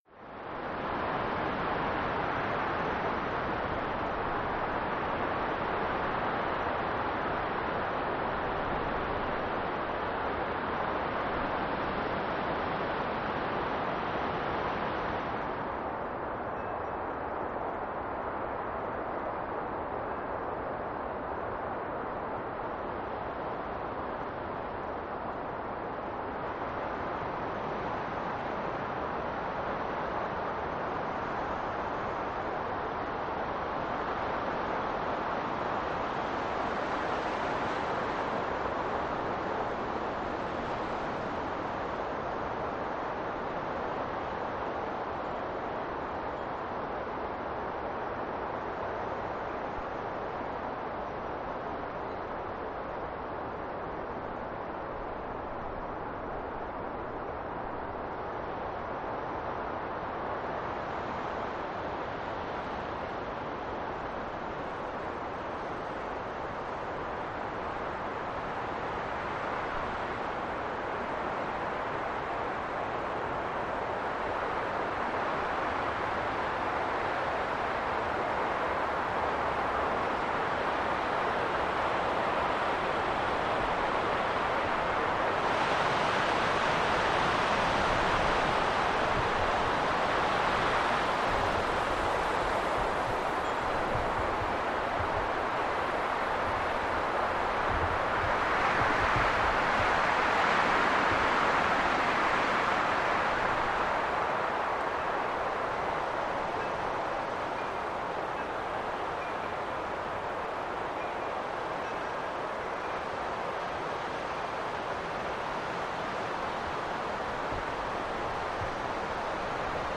Gusts|Cold | Sneak On The Lot
Blustery Snow Flurry, Night-time